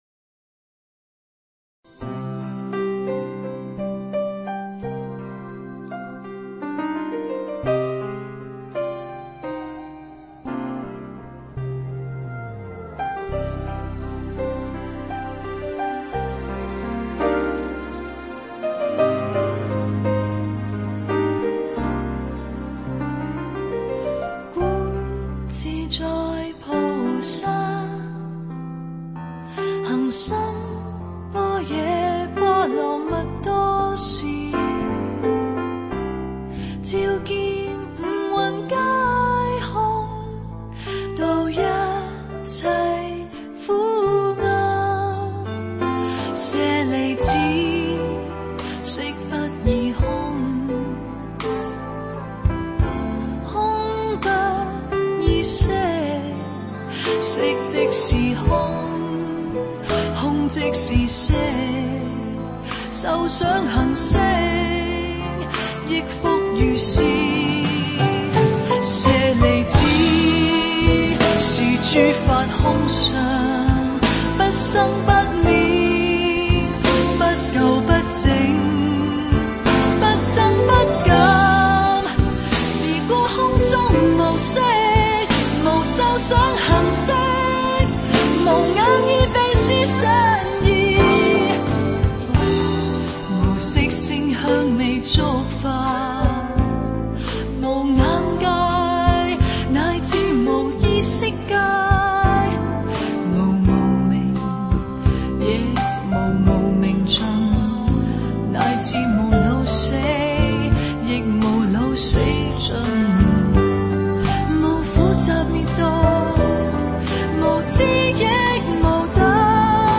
佛音 诵经 佛教音乐 返回列表 上一篇： 心经(粤语